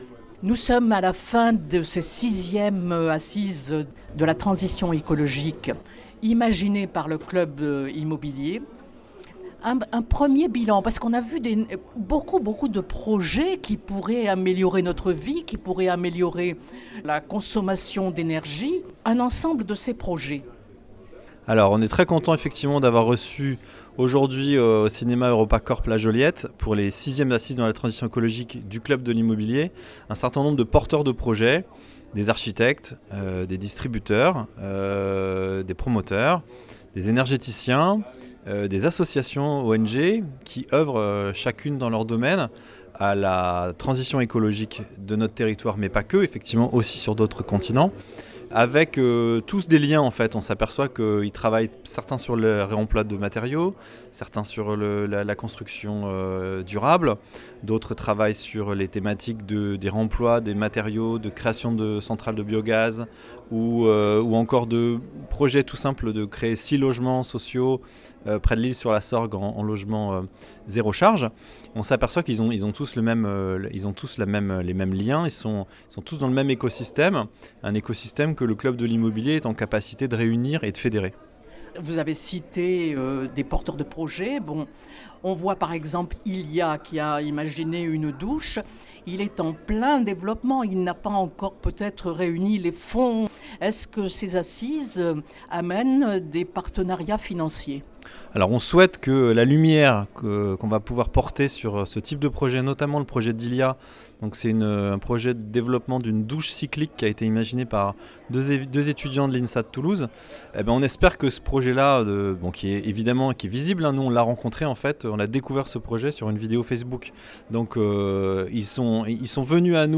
Propos recueillis par